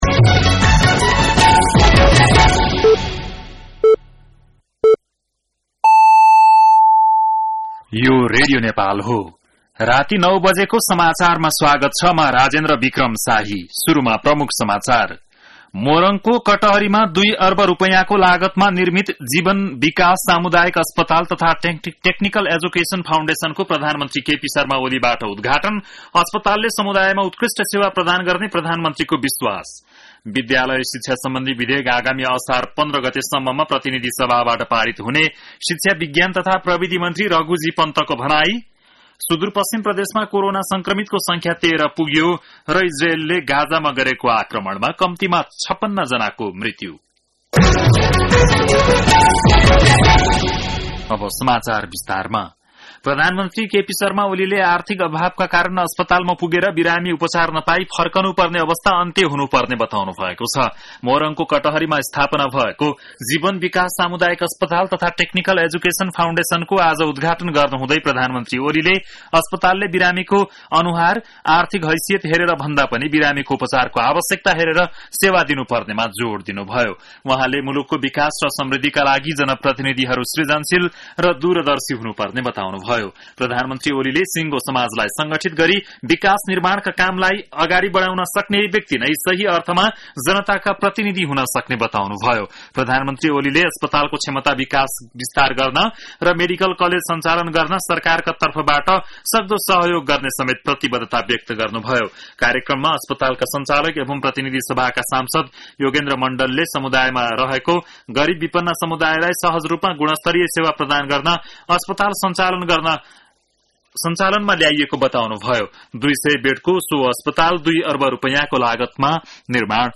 बेलुकी ९ बजेको नेपाली समाचार : २५ जेठ , २०८२
9.-pm-nepali-news-.mp3